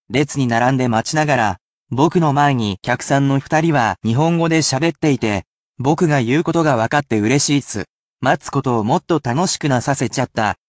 I can only read it at one speed, so there is no need to repeat after me, but it can still assist you in picking out vocabulary within natural speeds of speech.
rei ni narande machinagara, boku no mae ni kyakusan no futari wa nihongo de shabetteite, watashi ga iu koto ga wakatte ureshissu. matsu koto wo motto tanoshiku nasasechatta.